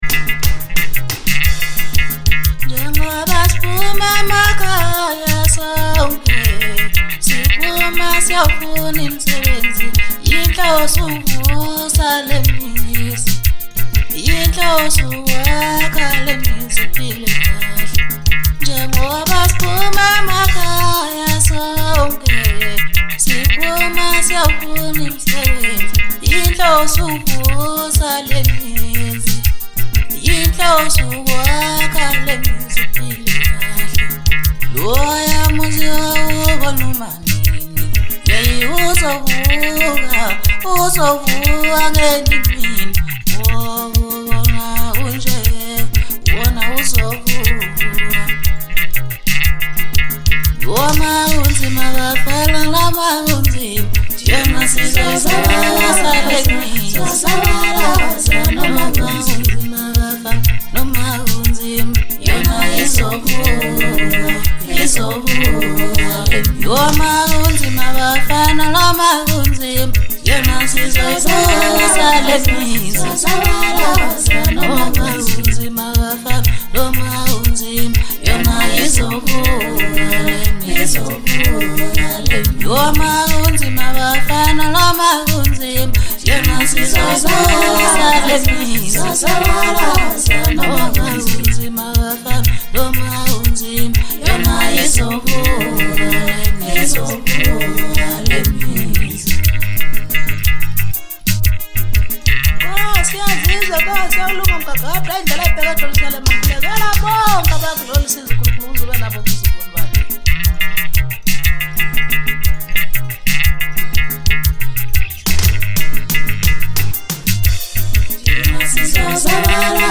Home » Maskandi » DJ Mix